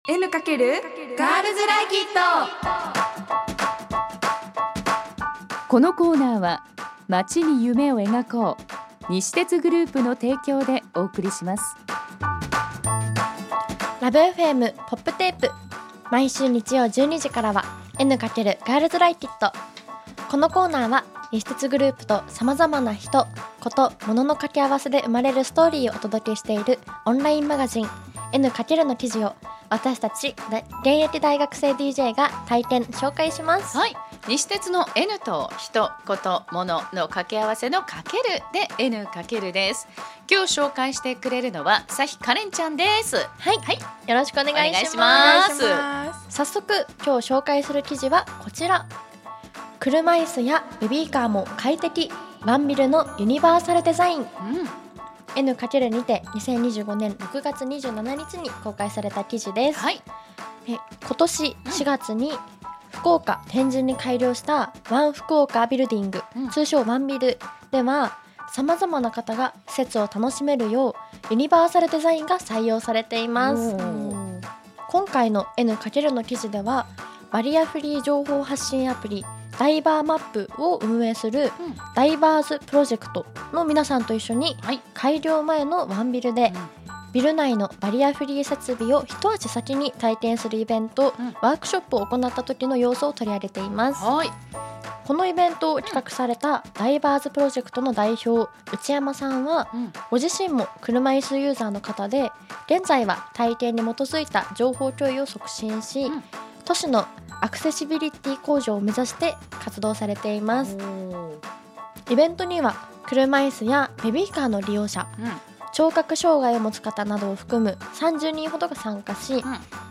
女子大生DJが「N× エヌカケル」から気になる話題をピックアップ！